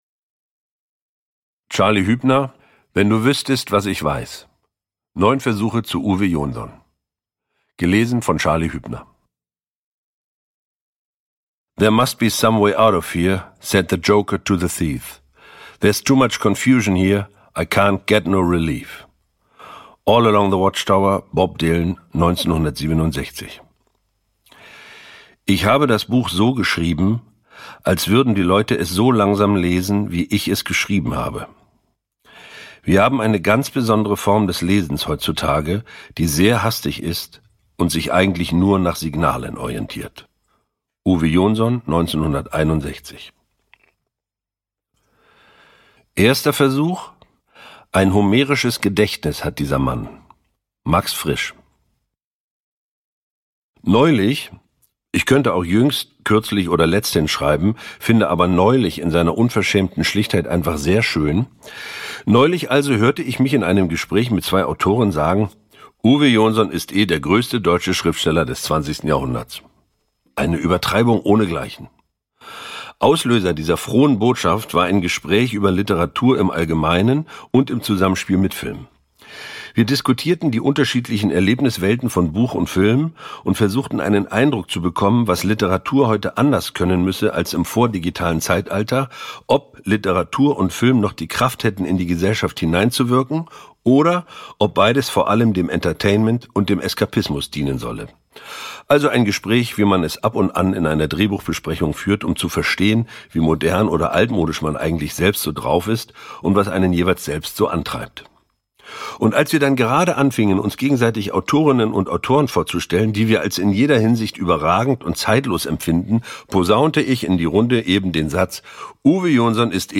Ungekürzte Autorenlesung (1 mp3-CD)
Charly Hübner (Sprecher)
Ungekürzte Autorenlesung mit Charly Hübner